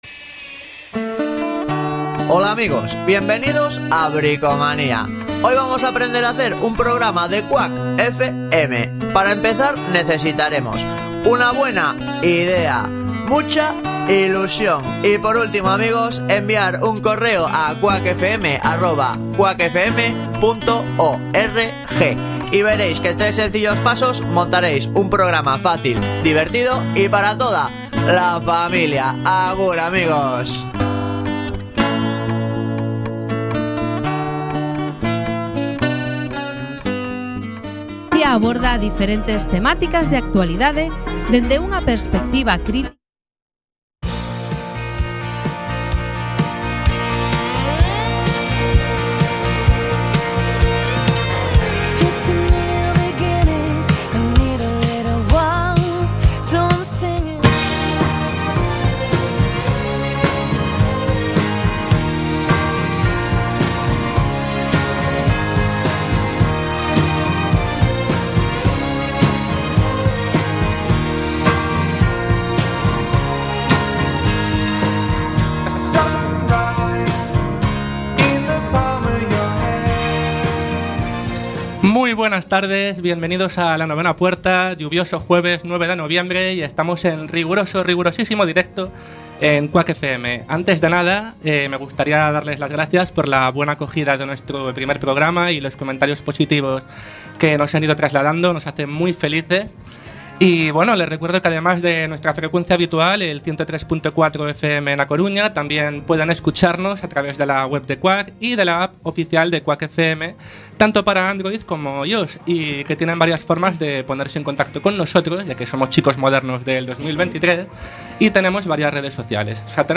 Programa de opinión y actualidad en el que se tratan temas diversos para debatir entre los colaboradores, con algún invitado relacionado con alguno de los temas a tratar en el programa y que además cuenta con una agenda de planes de ocio en la ciudad y alguna recomendación musical y de cine/series/libros.